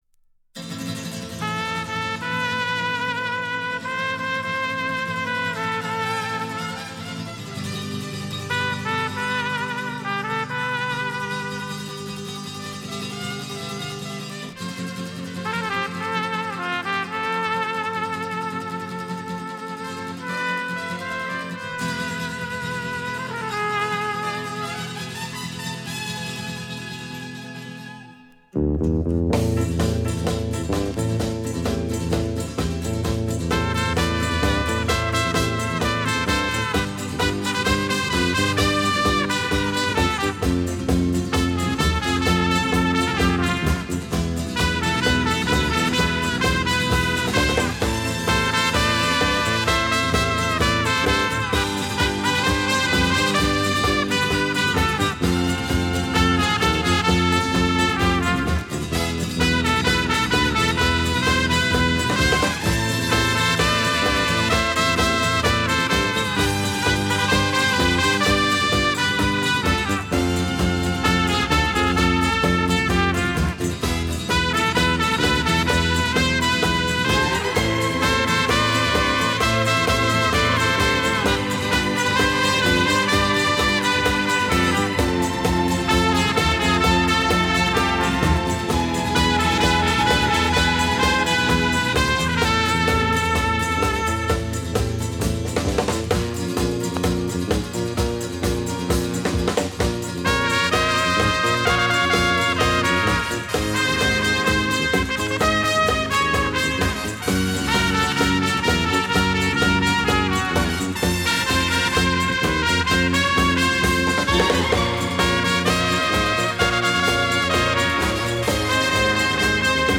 Оцифровка